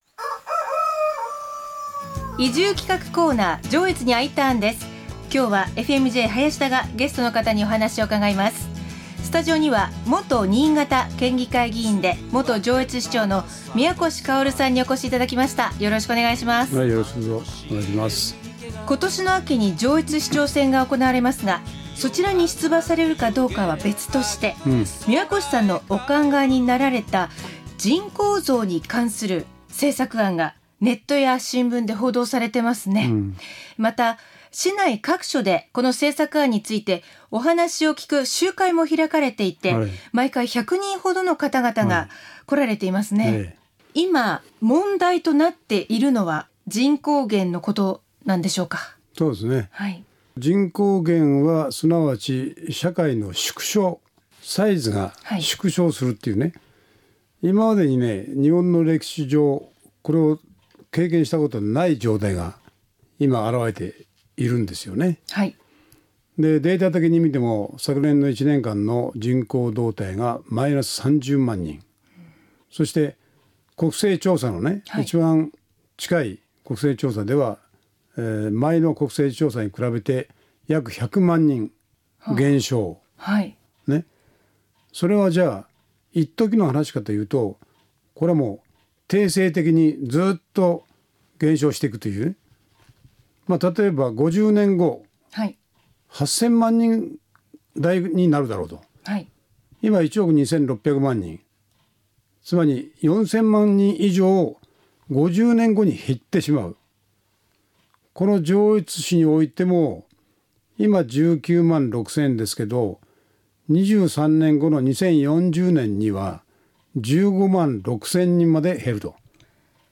FM-Jのスタジオから移住をお誘いするコーナー。
元県議で元上越市長の宮越馨さんのお話をお送りしました。